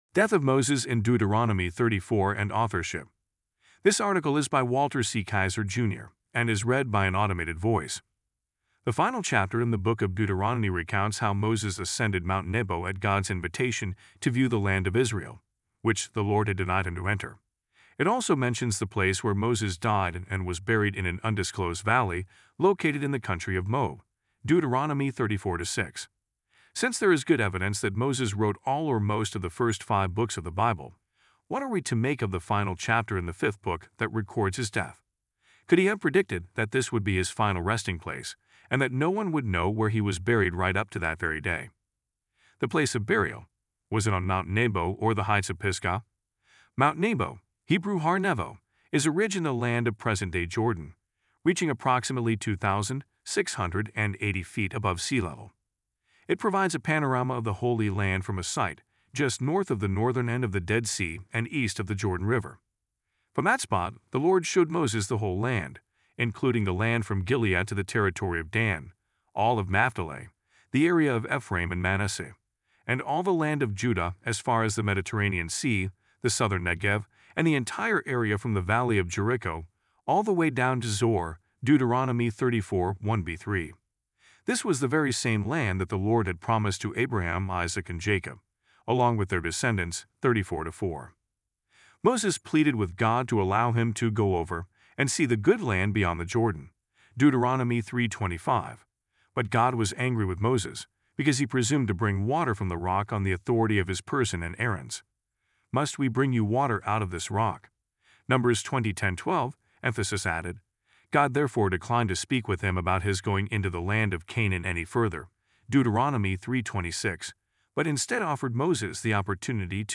AI-Death-of-Moses-in-Deut.-34-and-Authorship-Full-Article.mp3